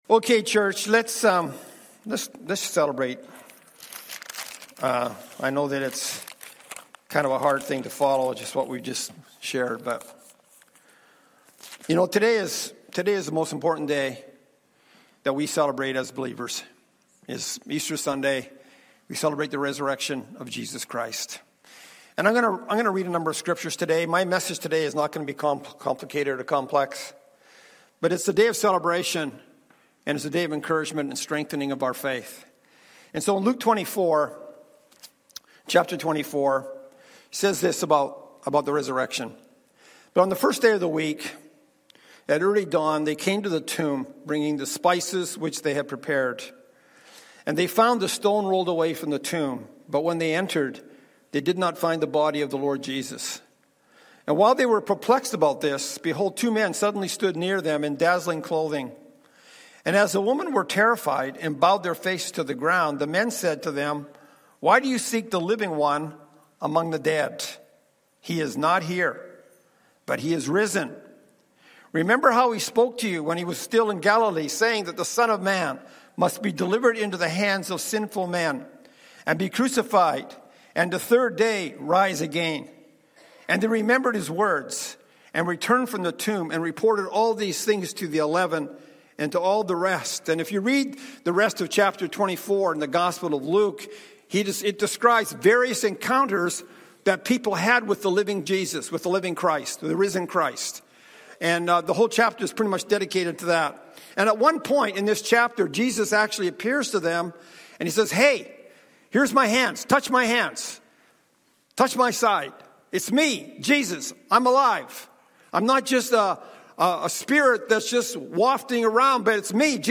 Stand Alone Message Passage